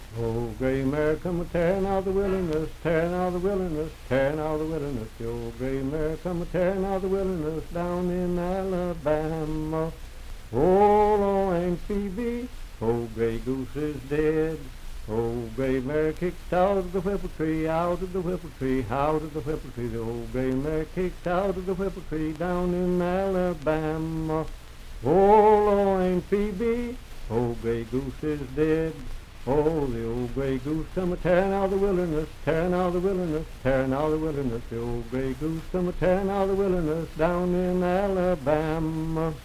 Unaccompanied vocal music
Verse-refrain 3(4) & R(2).
Voice (sung)
Pendleton County (W. Va.), Franklin (Pendleton County, W. Va.)